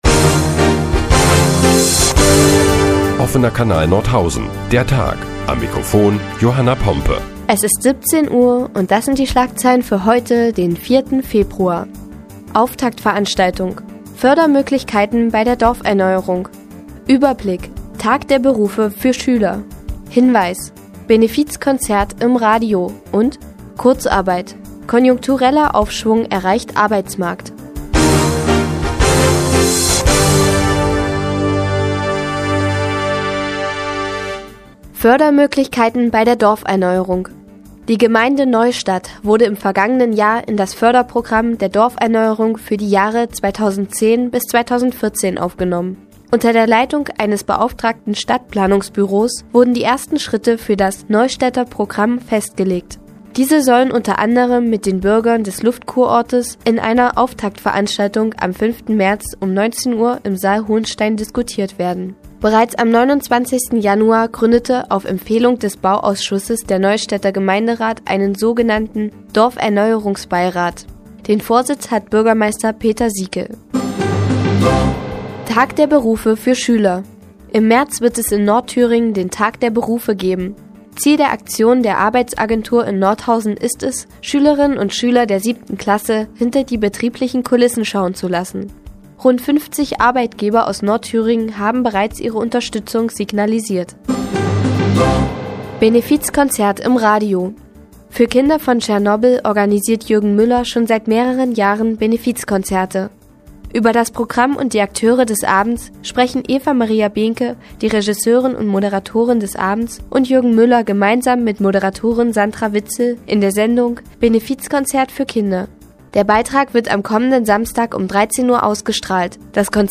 Die tägliche Nachrichtensendung des OKN ist nun auch in der nnz zu hören. Heute geht es unter anderem um konjunkturellen Aufschwung im Arbeitsmarkt und ein Benefizkonzert zugunsten der Kinder von Tschernobyl.